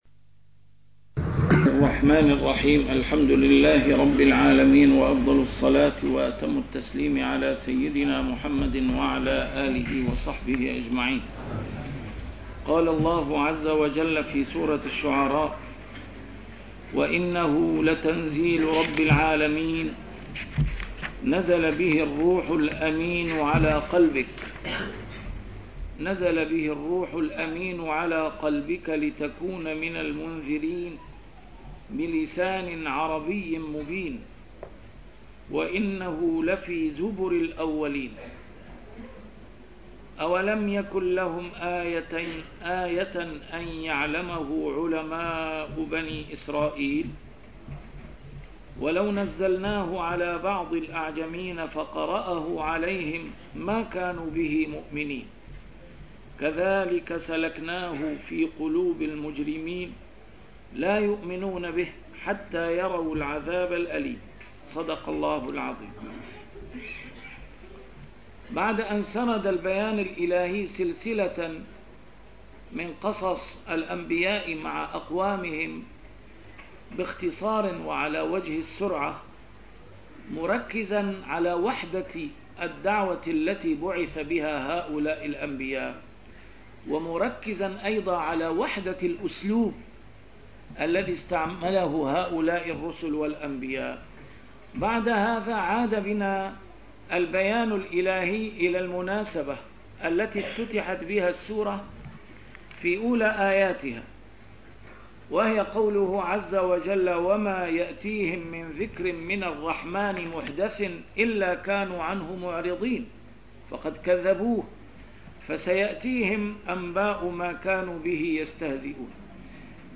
A MARTYR SCHOLAR: IMAM MUHAMMAD SAEED RAMADAN AL-BOUTI - الدروس العلمية - تفسير القرآن الكريم - تسجيل قديم - الدرس 238: الشعراء 192-196